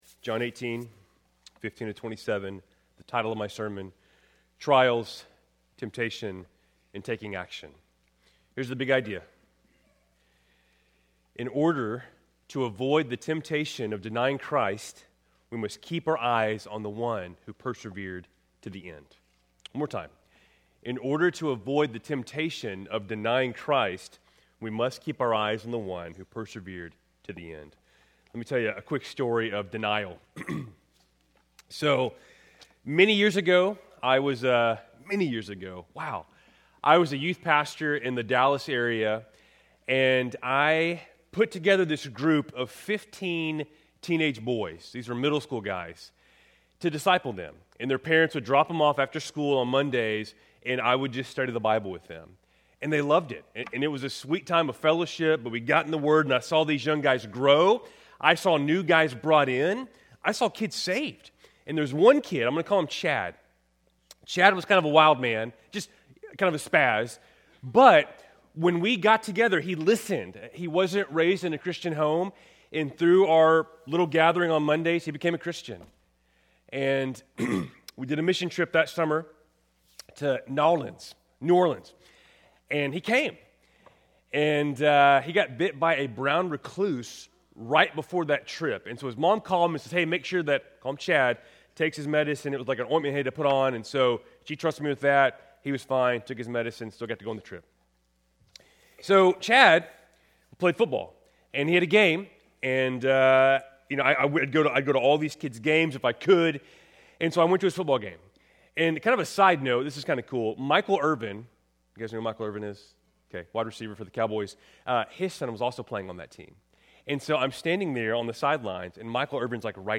Keltys Worship Service, January 18, 2026